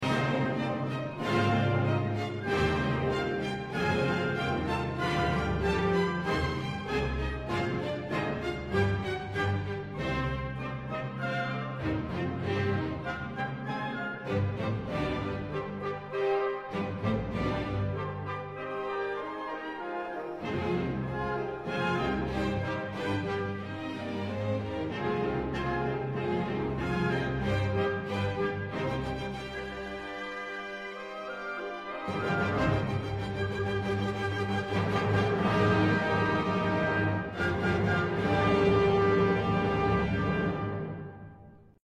Descriptive Description: Step into a legendary performance of Ludwig van Beethoven's Symphony No. 5 with the masterful Ivan Fischer conducting the Budapest Festival Orchestra. This isn't just a concert; it’s a living dramatization of a timeless tale.